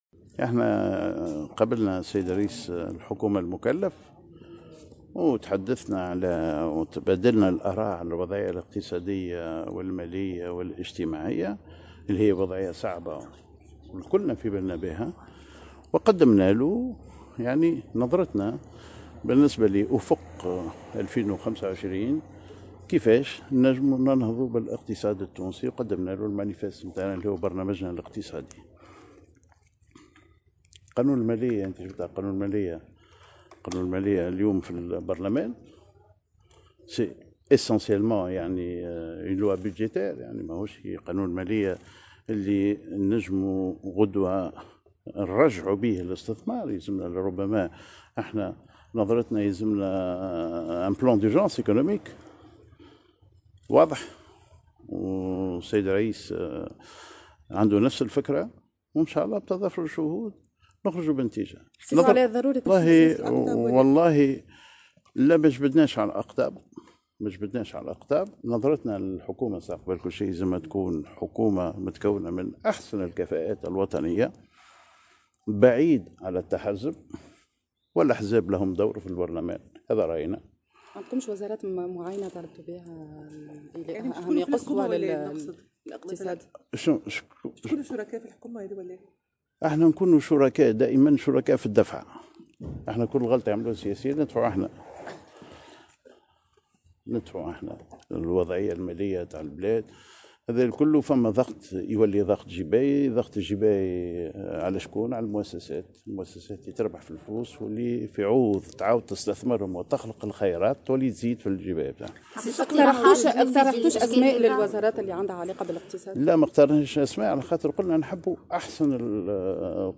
قال رئيس الاتحاد التونسي للصناعة و التجارة سمير ماجول في تصريح لمراسلة الجوهرة "اف ام" اليوم الجمعة 22 نوفمبر 2019 عقب لقاء جمعه برئيس الحكومة المكلف إن اللقاء تمحور حول تبادل الآراء حول الوضعية الاقتصادية و المالية والاجتماعية الصعبة.